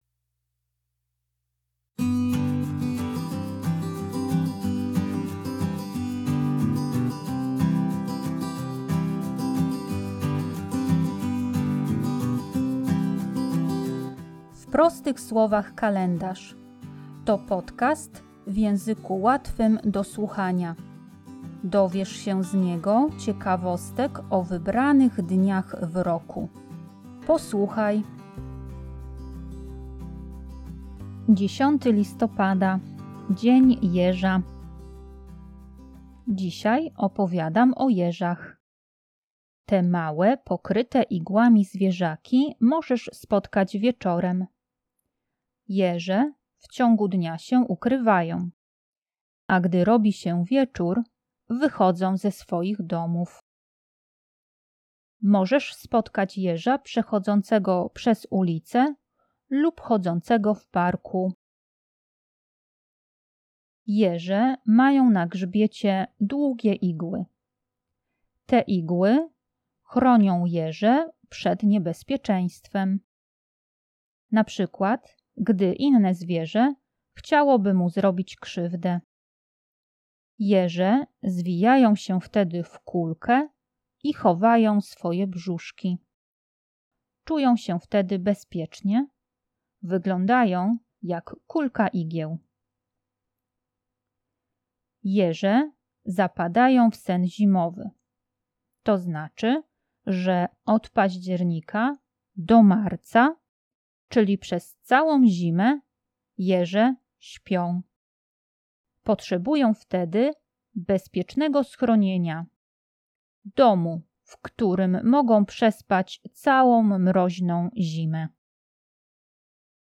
Tekst i lektorka